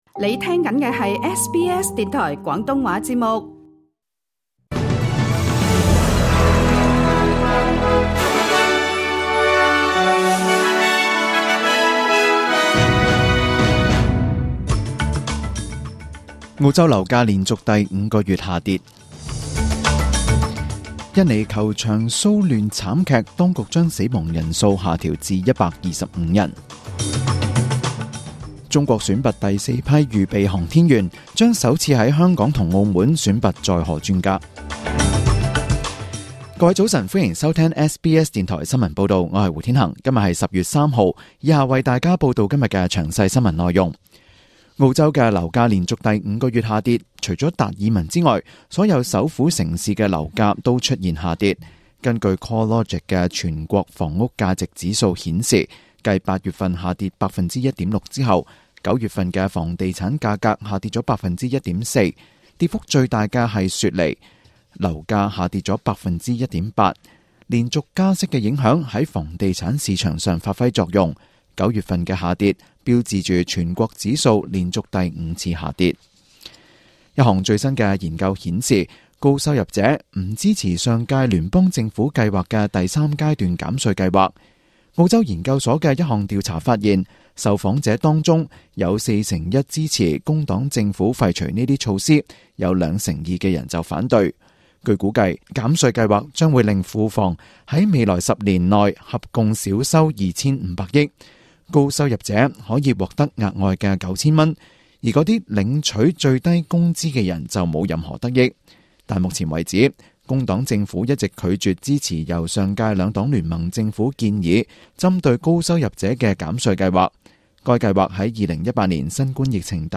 SBS 廣東話節目中文新聞 Source: SBS / SBS News